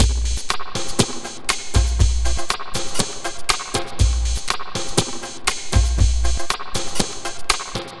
Astro 5 Drumz Wet.wav